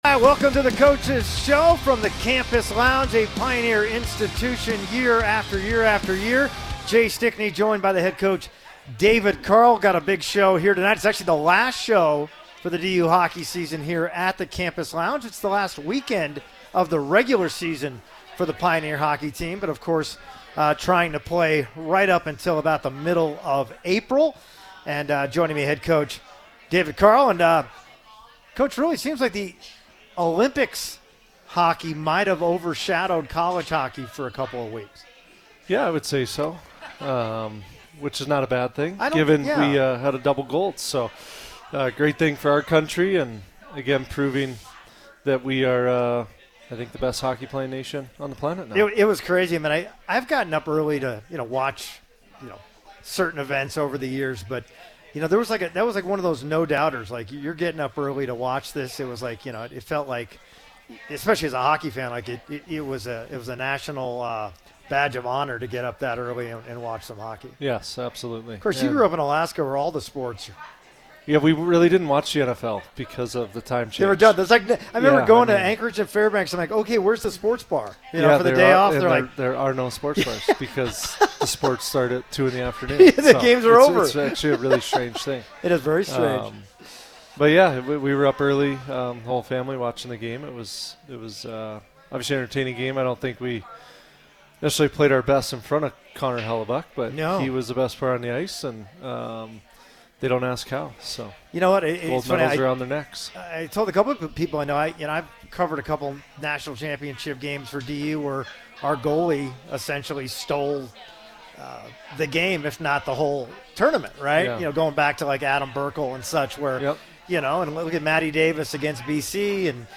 live DU Coaches Radio Show at the Campus Lounge